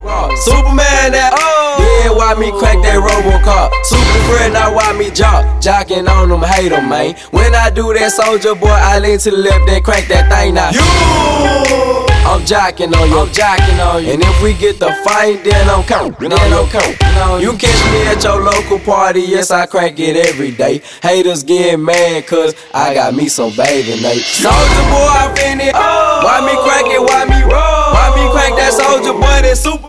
• Hip-Hop
The song is recognized by its looping steelpan riff.